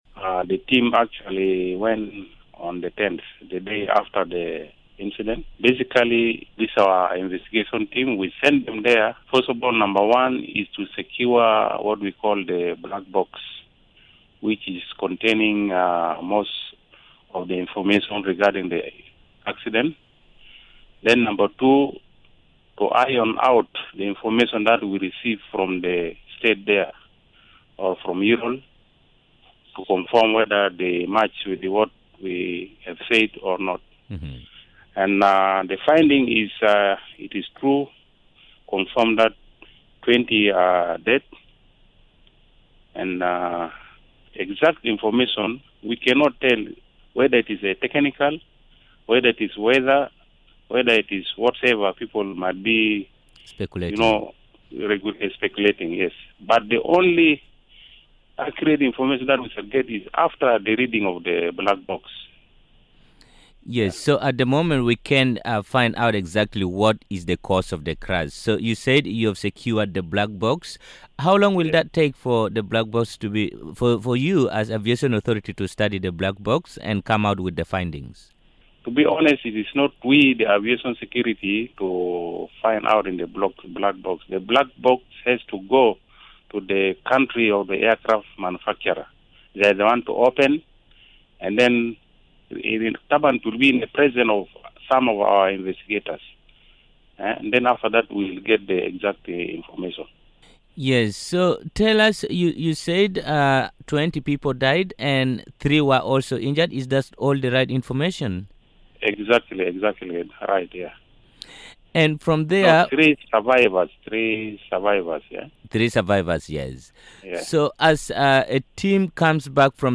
Speaking to Miraya Breakfast show, David Subek, the chief executive officer of the Civil Aviation Authority confirmed that the flight recorders technically called the black boxes have been recov-ered. The civil aviation chief explains what happens next now that the black box has been recovered.